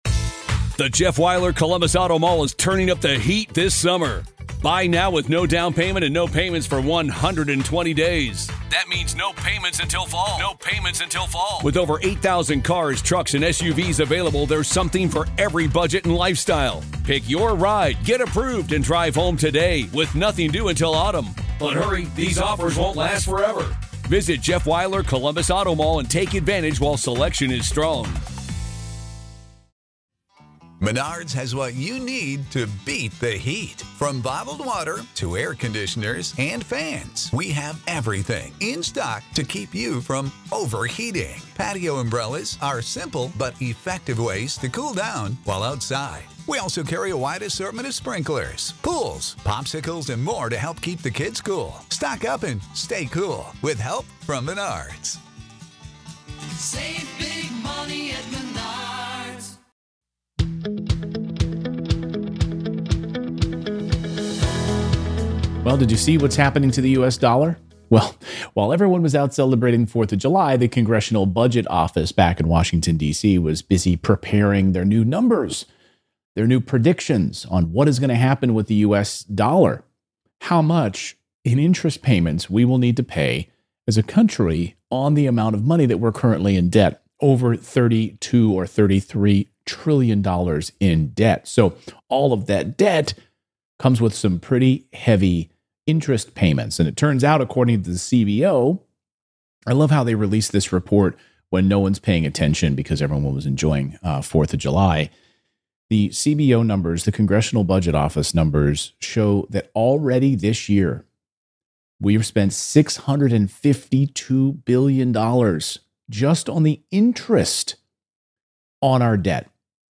Today's first caller asked a popular question. If you're looking to move out of your primary residence, is it a good idea to rent it out? Or is it better to cut ties completely and sell the property?